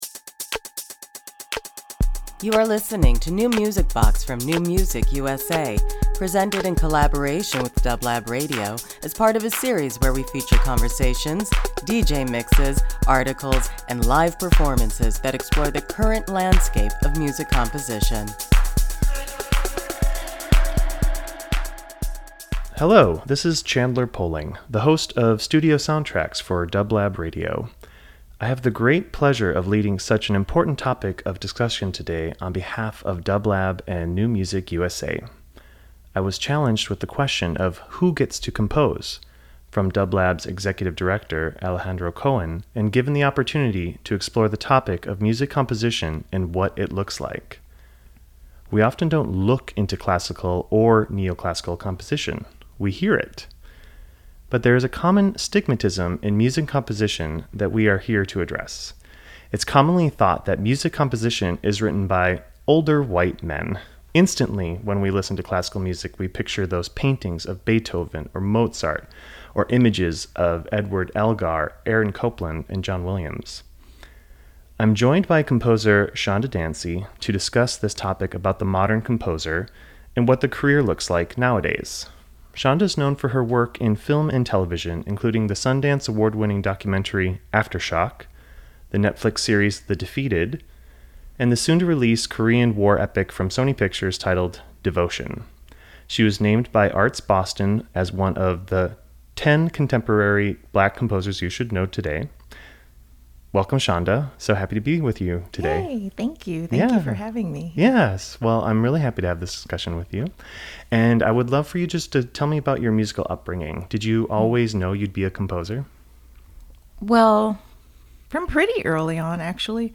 Interview Talk Show